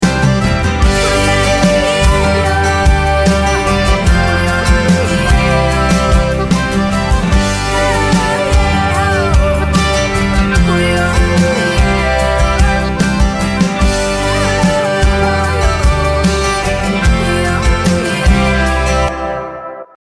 Heimat-Klingelton